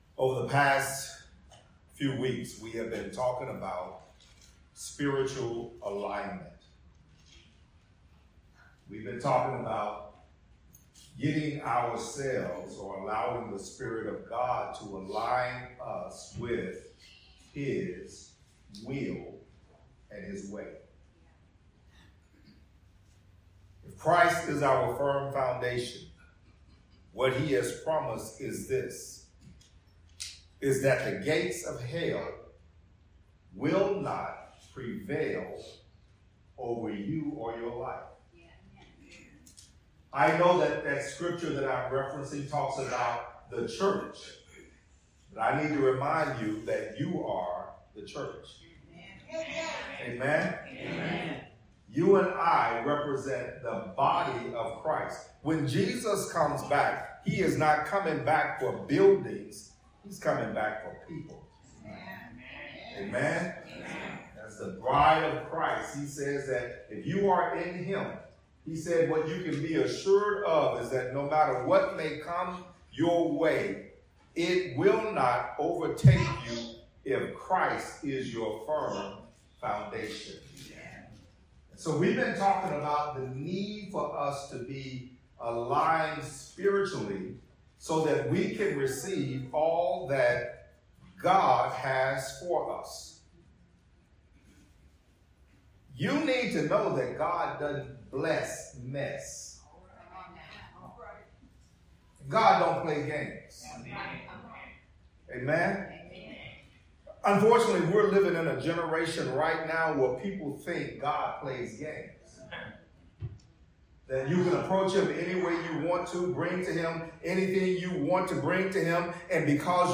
Sermons | New Joy Fellowship Ministry